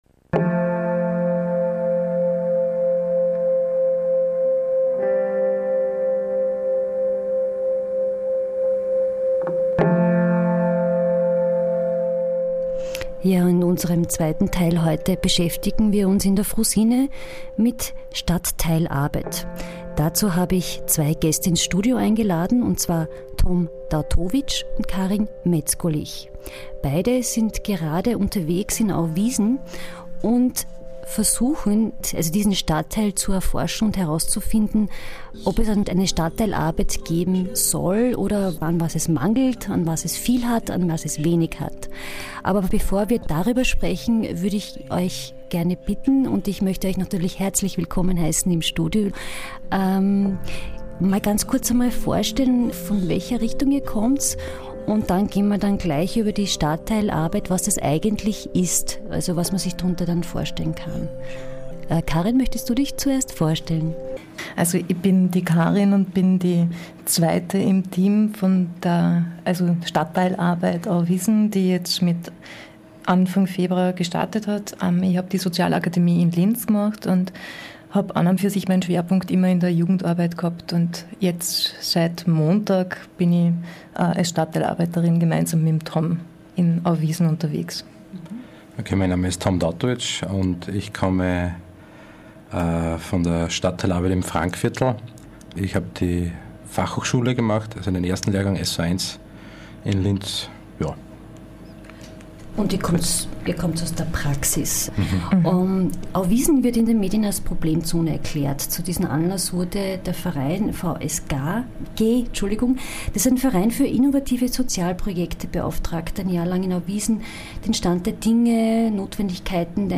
Format: Stereo 44kHz